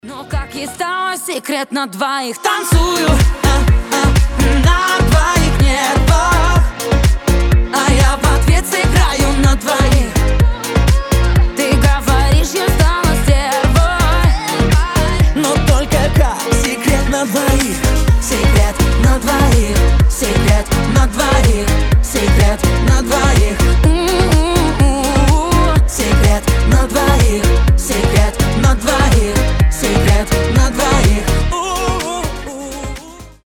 поп , танцевальные
дуэт